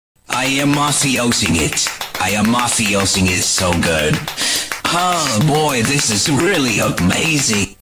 im mafioso ing it Meme Sound Effect